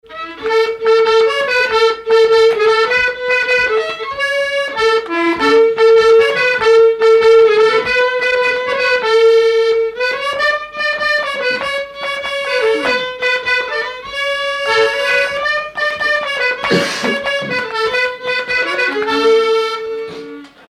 Sixt-Fer-à-Cheval
Pièce musicale inédite